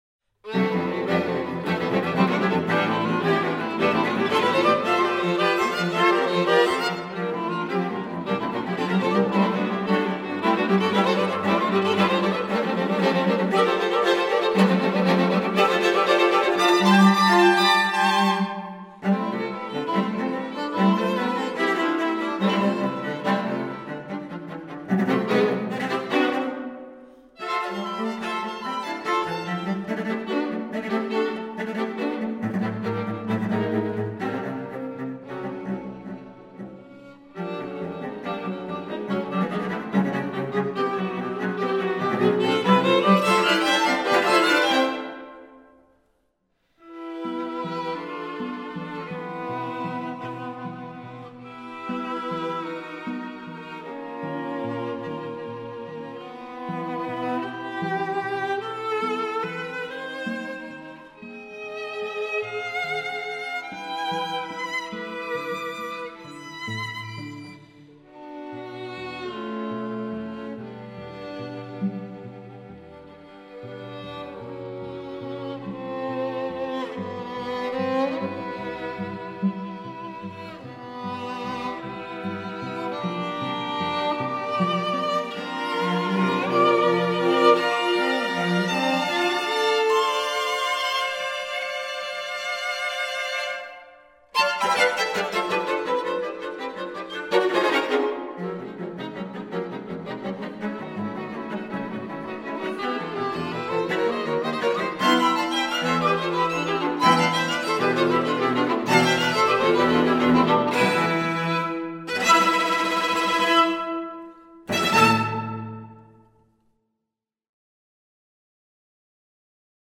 Presto 2:05